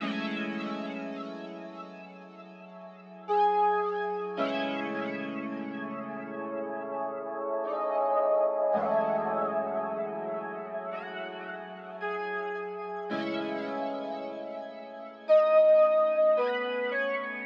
月亮垫
描述：在Logic Pro 9中用GarageBand的垫子制作。
标签： 110 bpm Ambient Loops Pad Loops 2.94 MB wav Key : Unknown
声道立体声